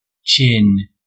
Ääntäminen
IPA : /tʃɪn/